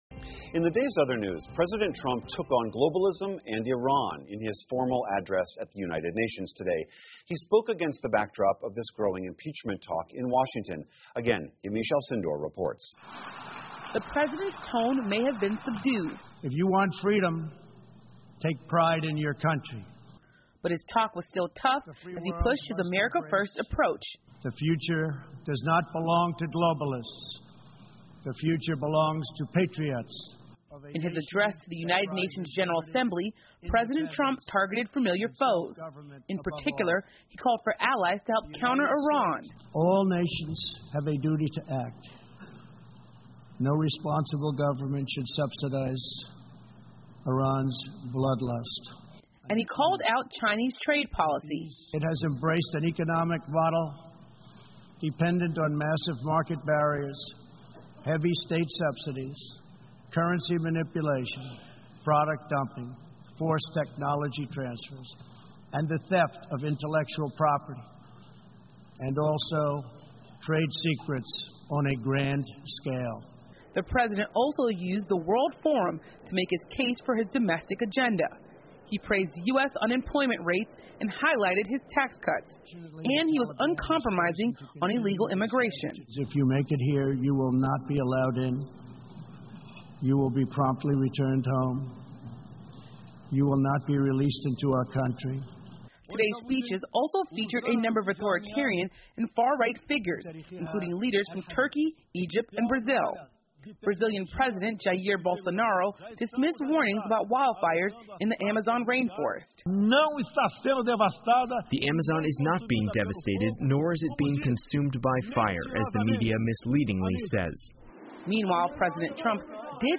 PBS高端访谈: 听力文件下载—在线英语听力室